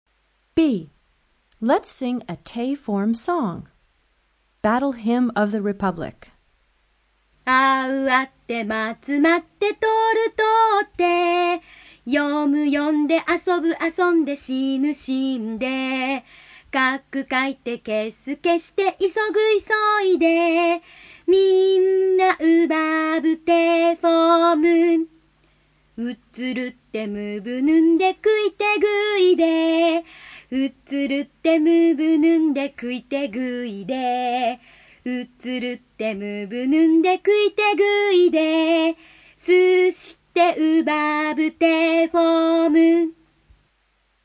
Lied
In jedem Fall ist das Lied sehr lustig zum hören.